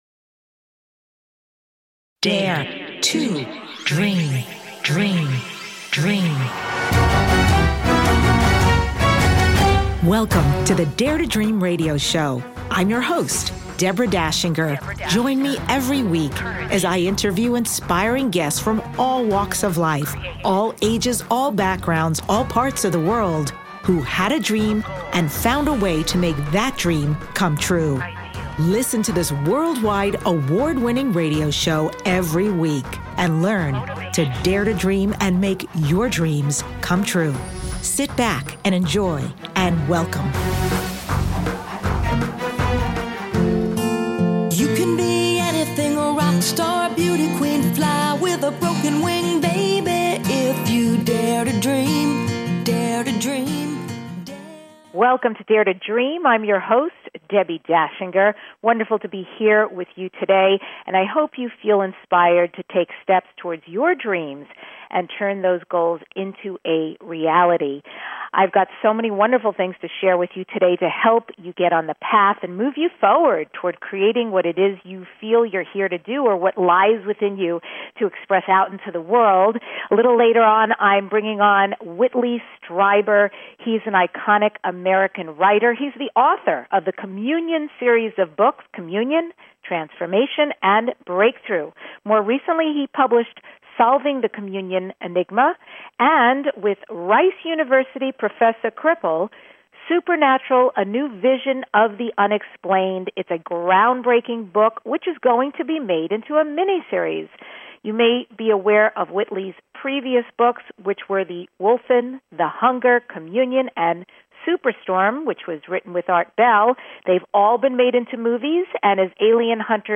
Guest, Whitley Strieber
The award-winning DARE TO DREAM Podcast is your #1 transformation conversation.